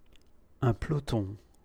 pronounce each phrase.